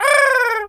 pigeon_call_angry_08.wav